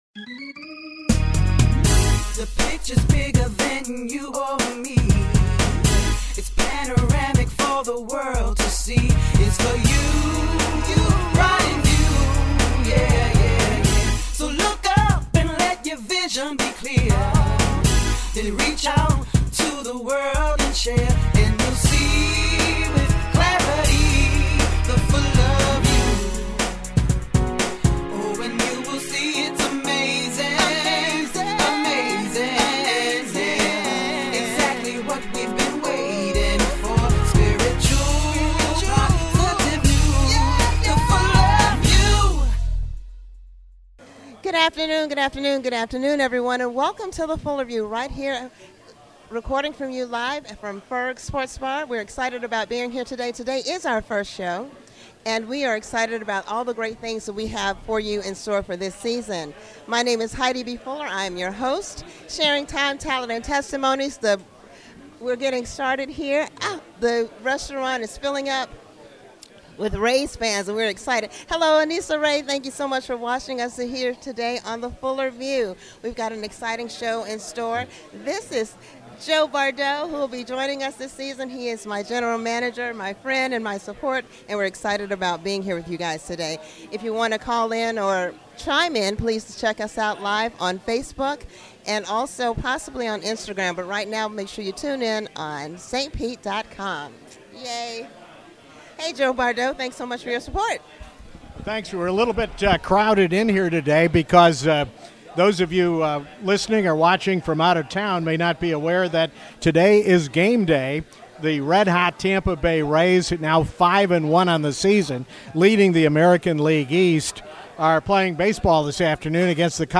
Live from Ferg's